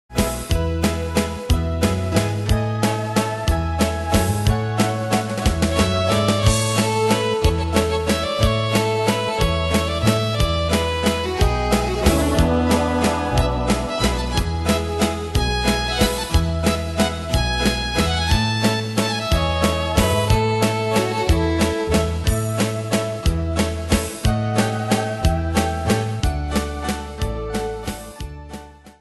Style: Country Ane/Year: 1972 Tempo: 91 Durée/Time: 2.03
Danse/Dance: Valse/Waltz Cat Id.
Pro Backing Tracks